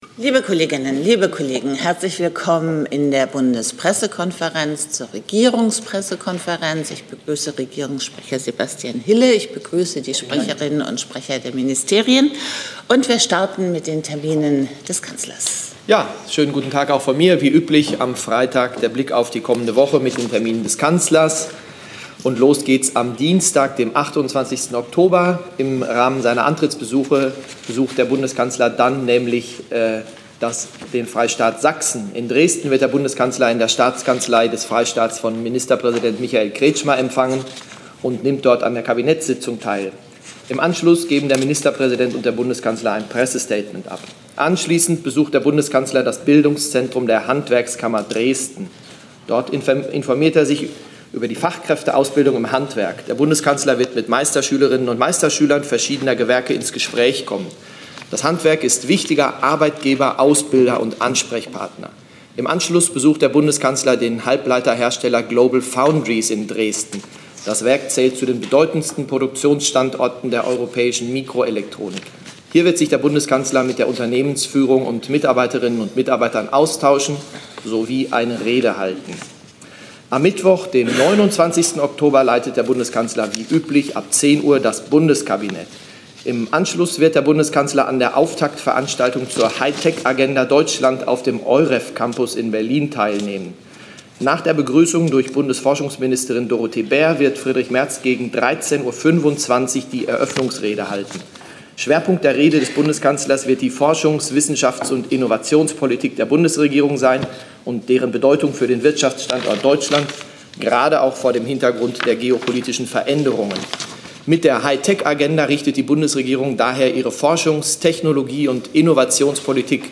Regierungspressekonferenz in der BPK vom 24. Oktober 2025